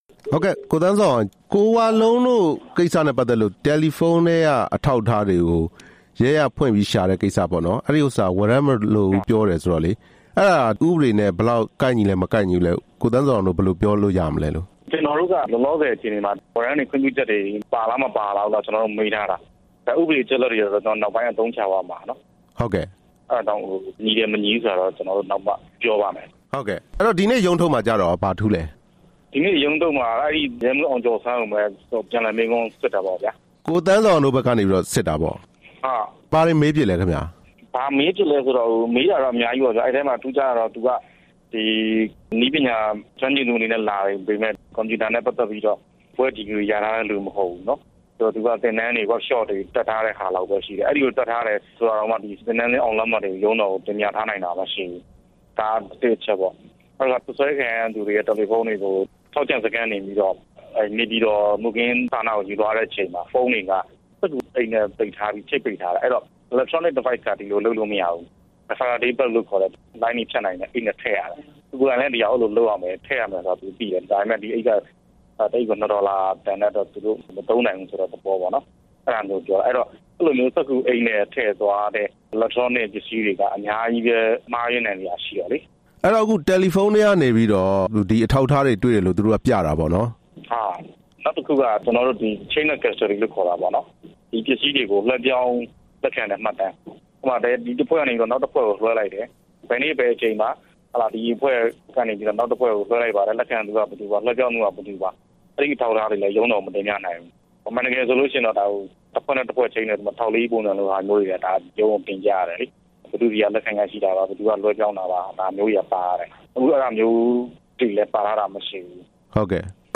ဆက်သွယ်မေးမြန်းထား ပါတယ်။